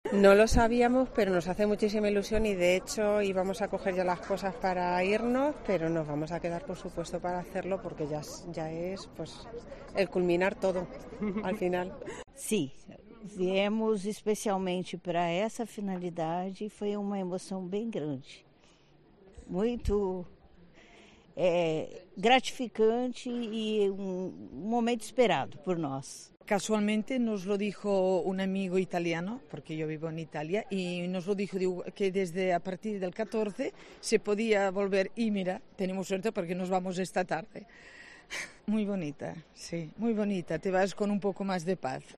Varias peregrinas nos contaron esta mañana la emoción de volver a abrazar la imagen del Apóstol